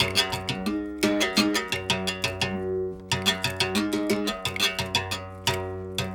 32 Berimbau 09.wav